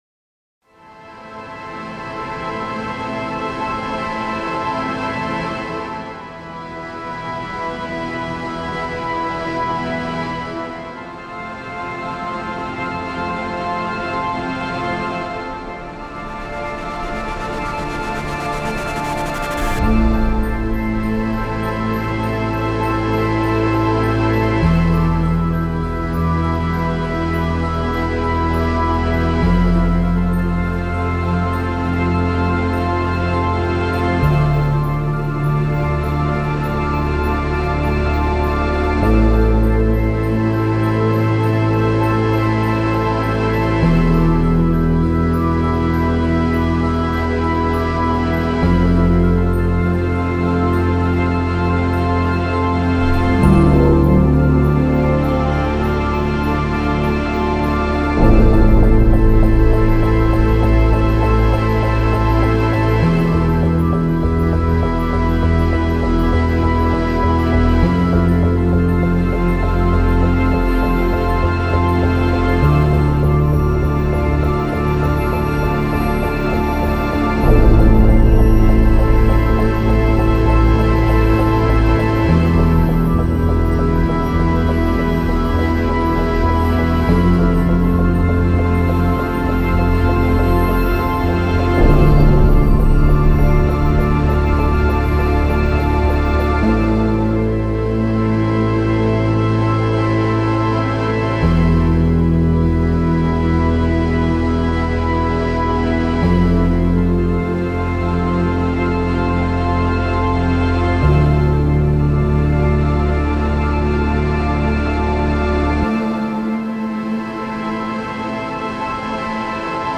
• テンポ：ゆったりとしたBPM（おおよそ60〜70程度）で、ビート感は薄く、拍を意識させない構成。
• 音色：主にパッド系シンセ、フェーズの揺れが心地よいサウンドを重ねています。中心にある持続音が幻想感を支えています。
• 展開：明確なA/B構成ではなく、徐々に音が変化していくアンビエント形式。環境音楽としての役割を意識。
• ミキシング：高域はややロールオフし、中低域に重心を置いたまろやかな仕上がり。ヘッドホンリスニングでも疲れにくい構成。
宇宙 幻想的 アンビエント